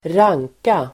Uttal: [²r'ang:ka]